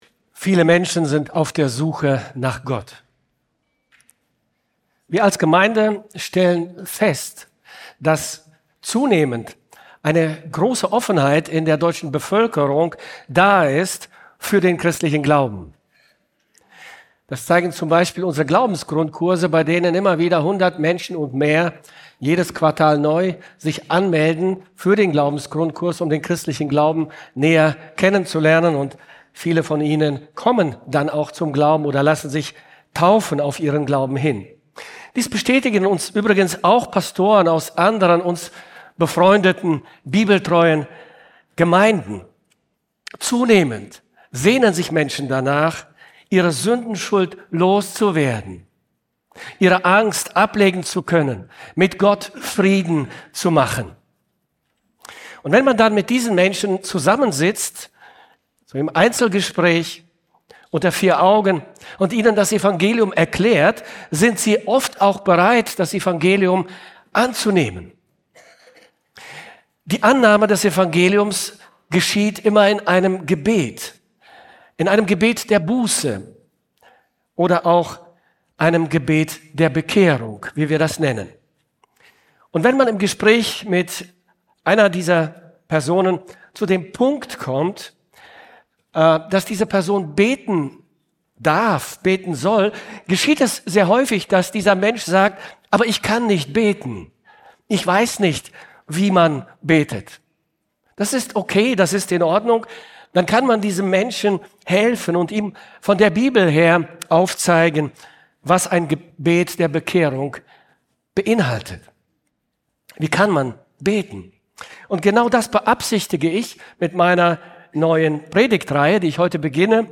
Juli 2025 Predigt-Reihe